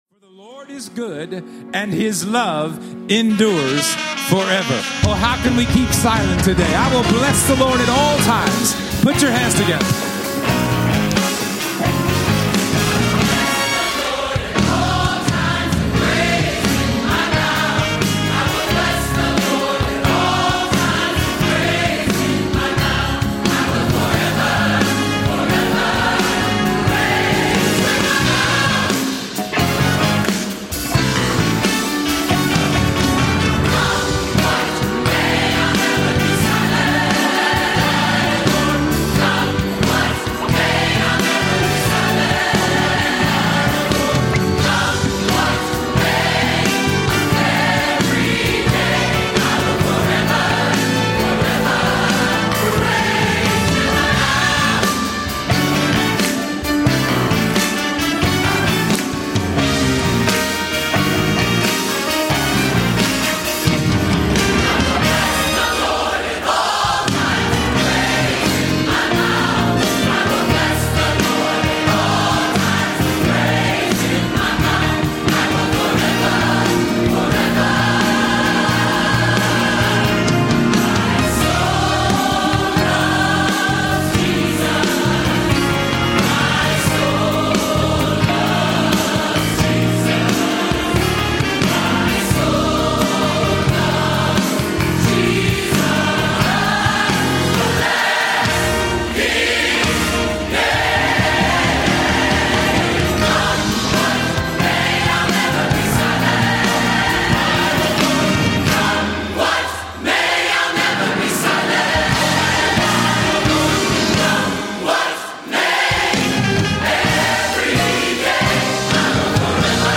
we-cannot-be-silent-mount-paran.mp3